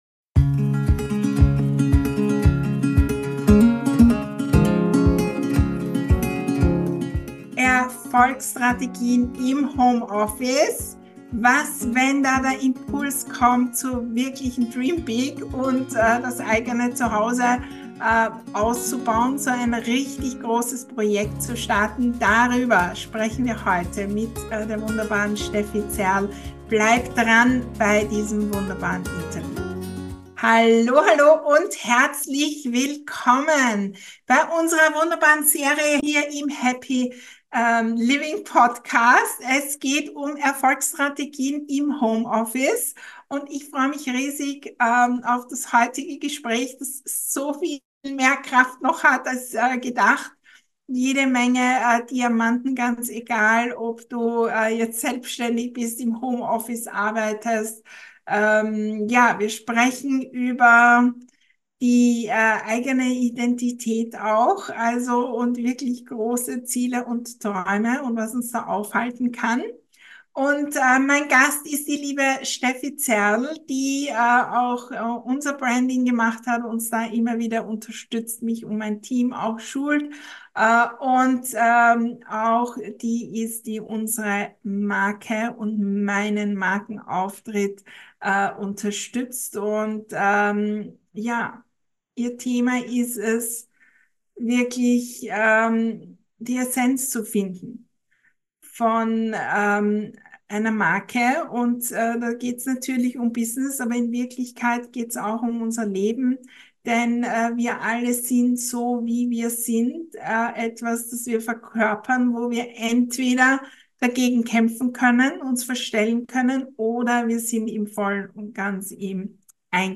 Ganz egal, ob du selbständig bist oder im Homeoffice arbeitest, in diesem Podcast-Interview findest du jede Menge Diamanten, Erkenntnisse und Motivation für deinen Erfolg.